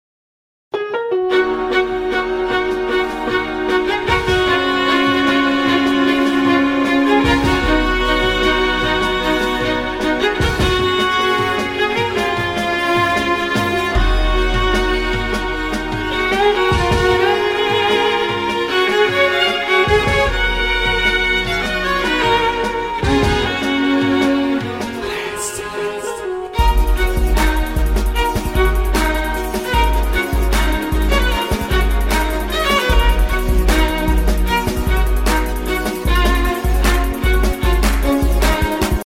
• Качество: 128, Stereo
скрипка
Cover
инструментальные